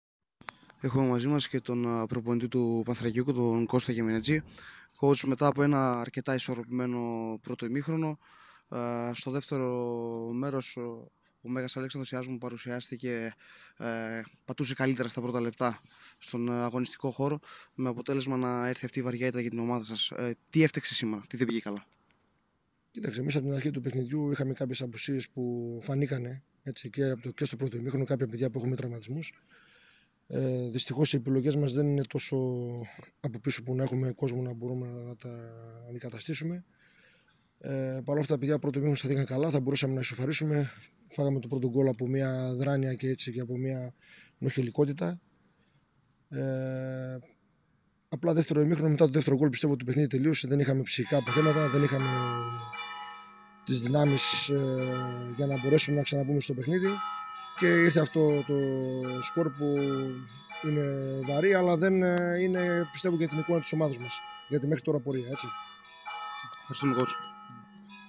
Οι δηλώσεις